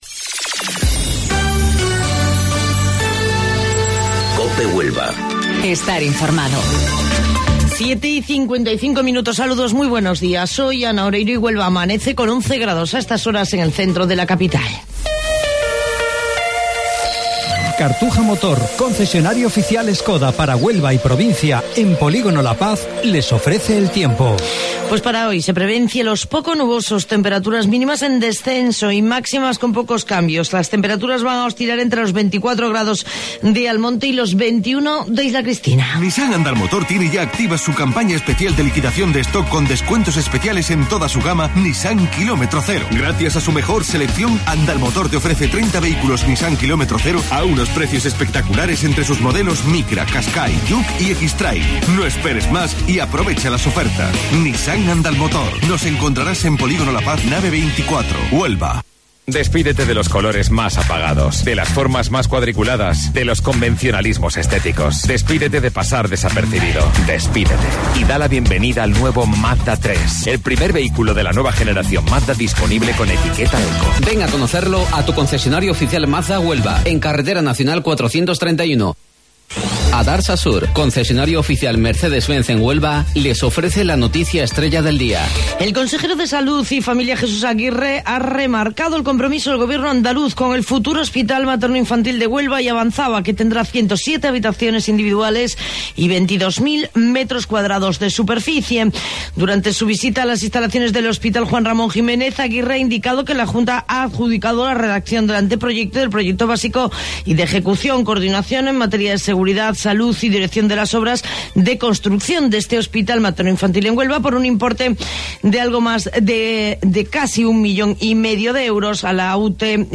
AUDIO: Informativo Local 07:55 del 28 de Marzo